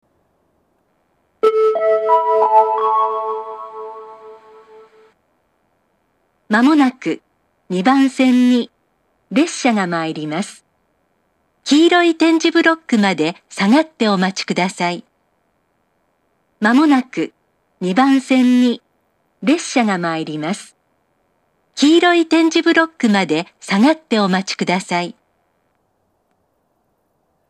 ２番線接近放送
また、スピーカーが兼用なので混線することもあります。